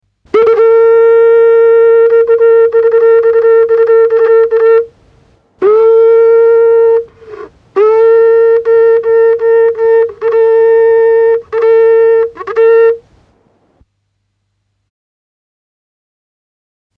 Música mapuche
Música tradicional
Folklore
Música vocal